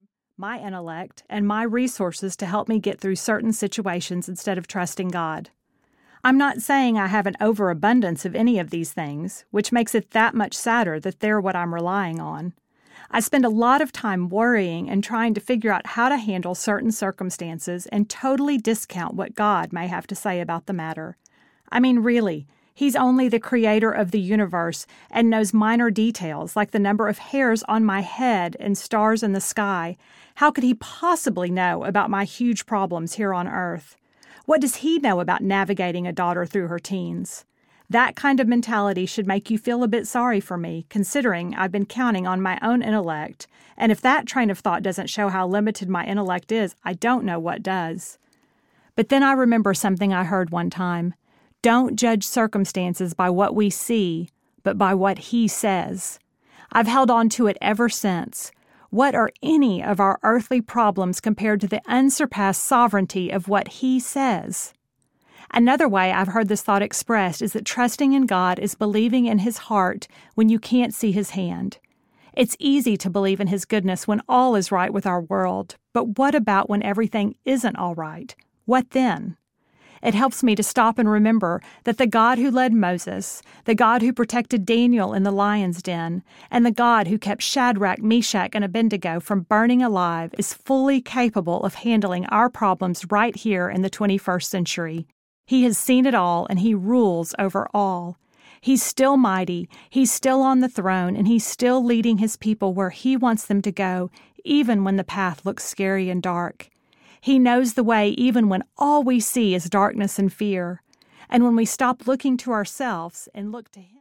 Everyday Holy Audiobook